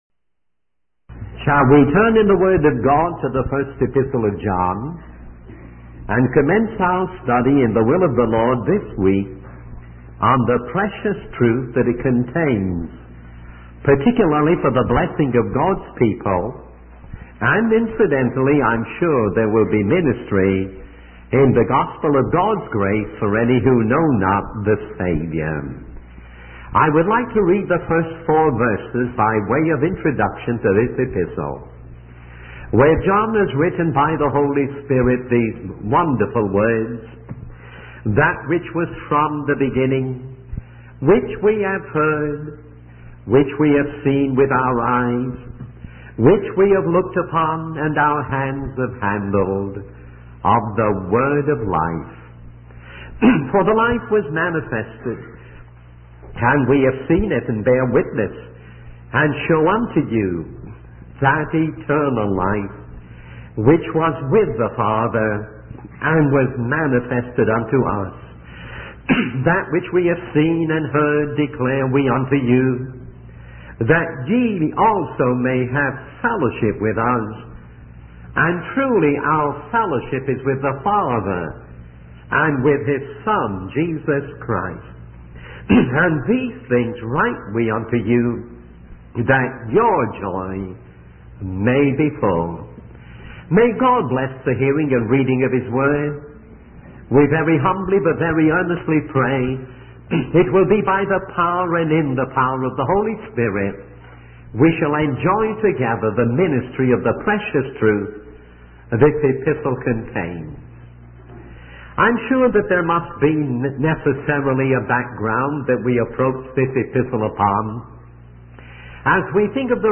He then reads the first four verses of the epistle, which highlight the person of Christ as the Word of Life.